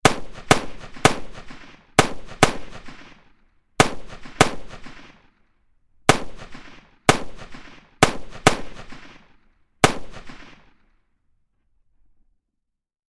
На этой странице представлены аудиозаписи, имитирующие звуки выстрелов.
Звук выстрелов в голову при массовой казни